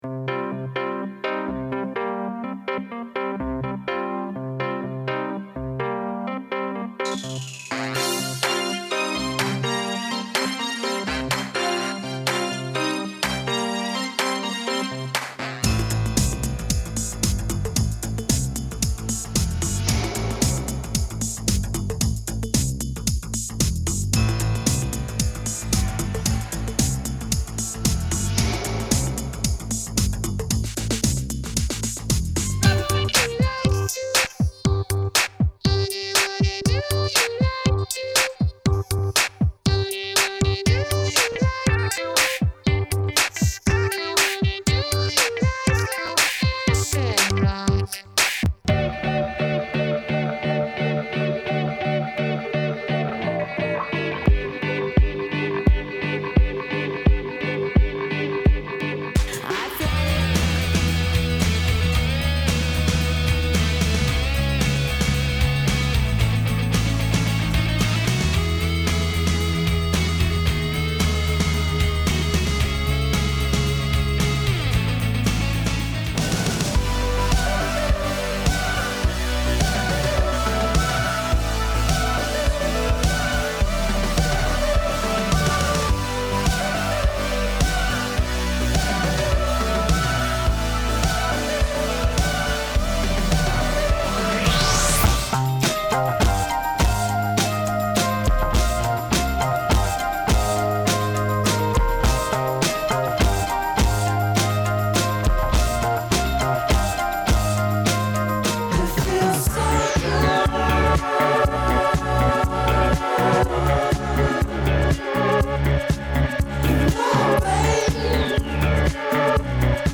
Hit play below and you’ll hear snippets from ten songs, all mixed together.